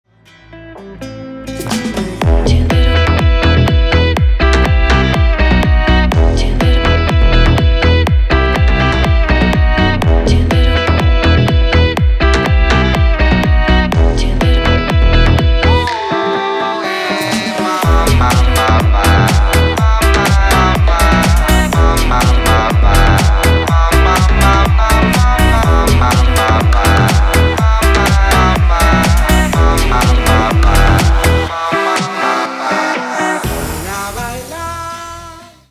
• Качество: 320, Stereo
dance
Electronic
мужской и женский вокал
Стиль: deep house